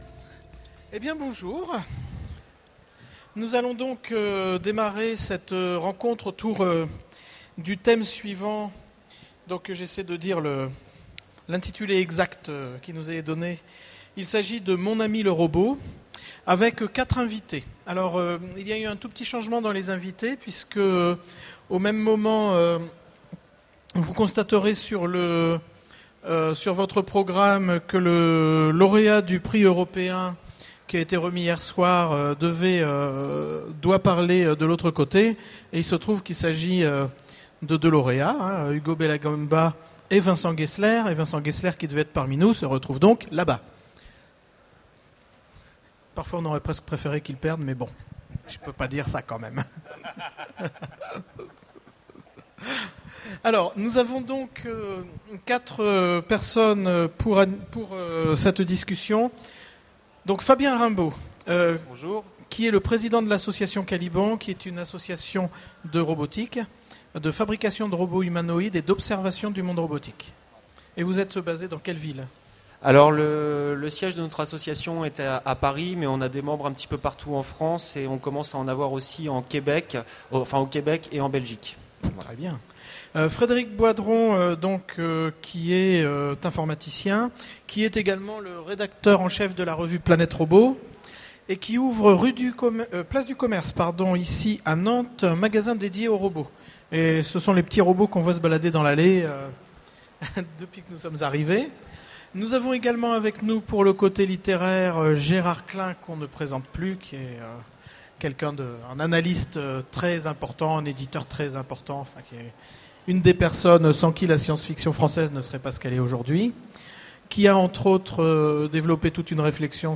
Voici l'enregistrement de la conférence " Mon ami le robot " aux Utopiales 2010. Notre vie quotidienne nous amène de plus en plus à interagir avec des machines intelligentes (bornes interactives, téléphones ou ordinateurs). À quand le robot-enfant, les robots animaux ou d’assistance à domicile ?